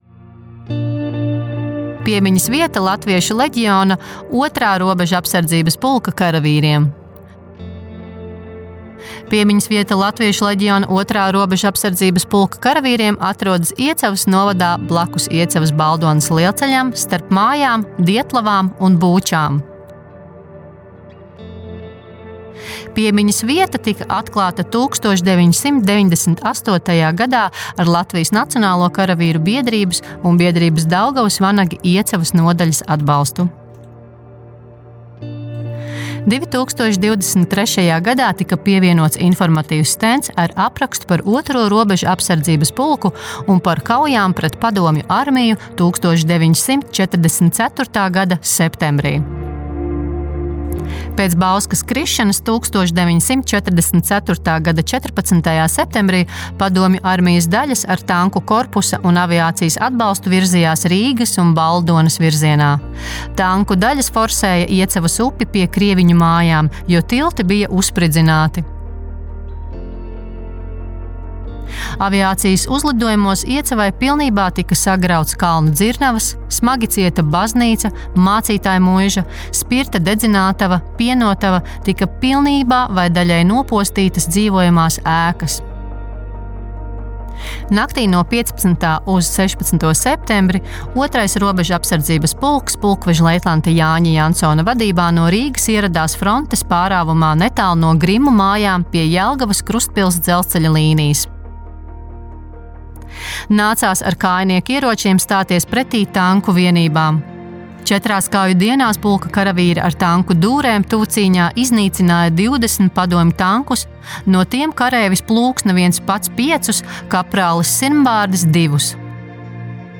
AUDIO STĀSTĪJUMS